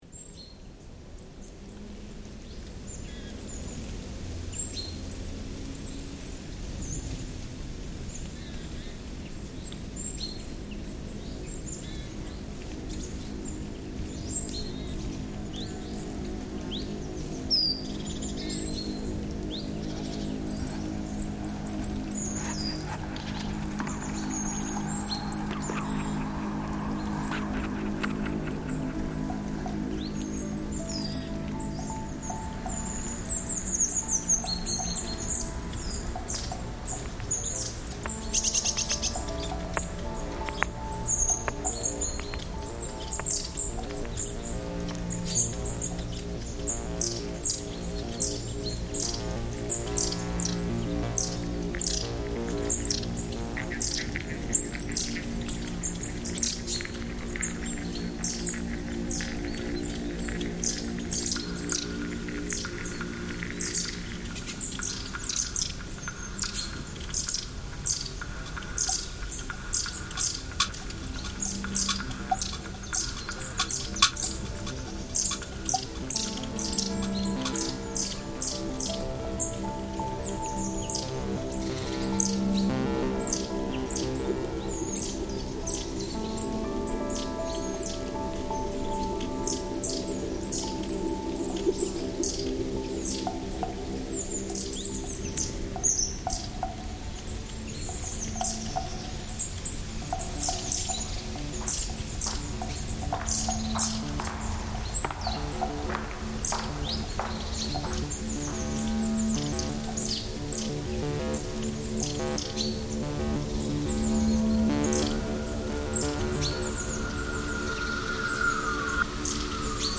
based on a field recording